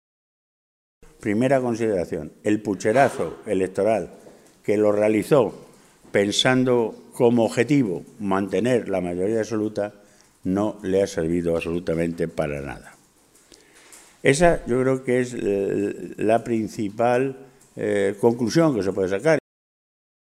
En una comparecencia ante los medios de comunicación, esta mañana, en Toledo, ha cifrado la distribución de escaños en 14 para el PSOE, 12 para el PP, 5 para Ciudadanos y 2 para Podemos.
Cortes de audio de la rueda de prensa